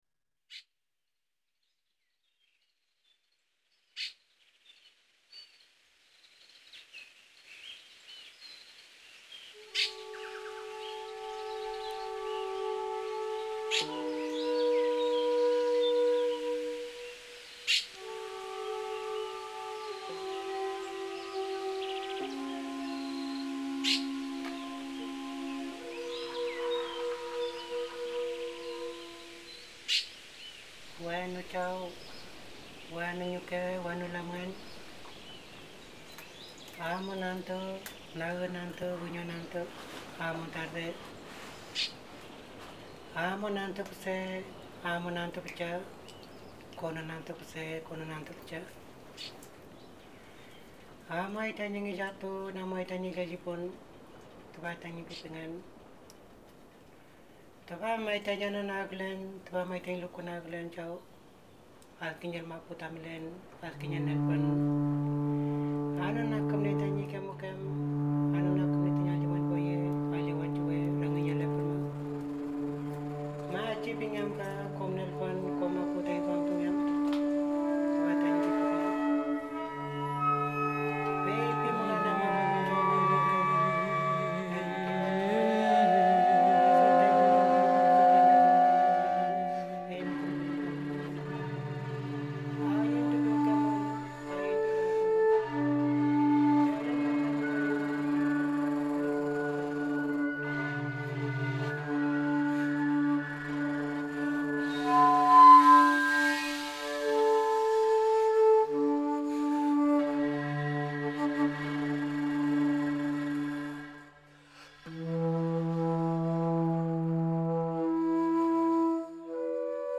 Pewenmapu para flautas, voces femeninas, pinkulwe y banda sonora